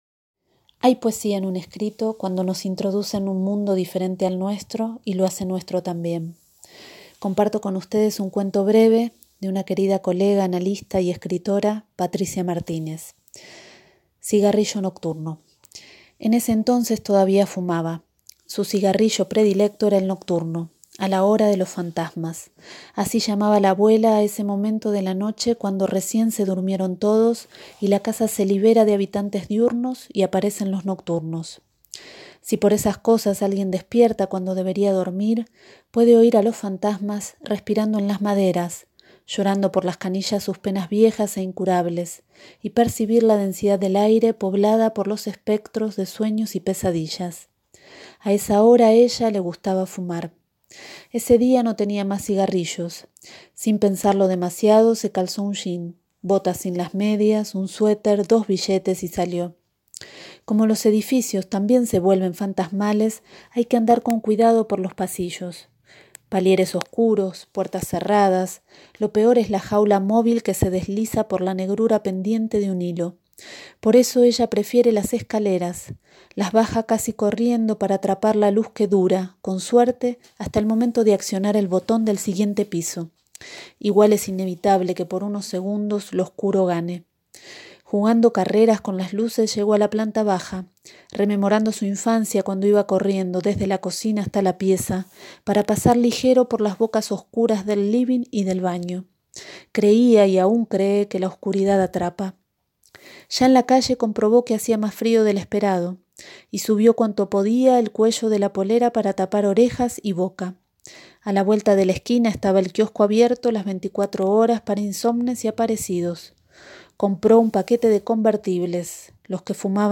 Cigarillo nocturno cuento de Patricia Martínez leído por